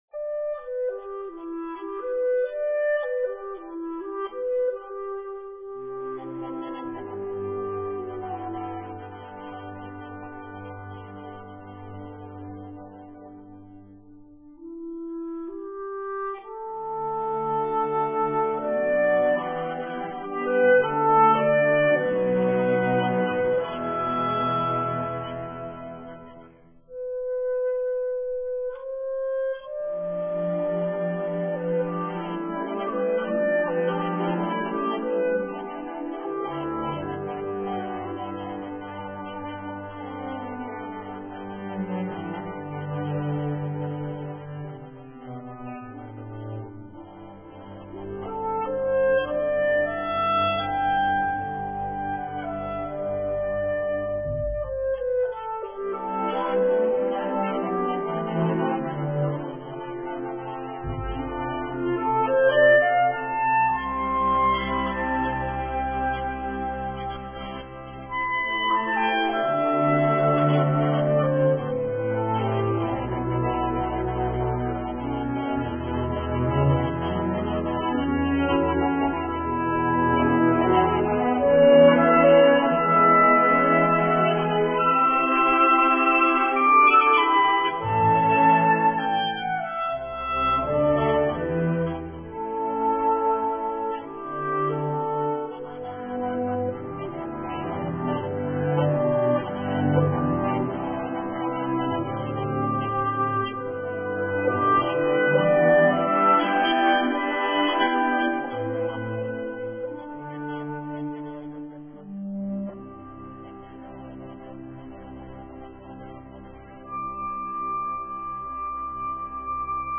klarinet
má jen malej počet vyšších harmonickejch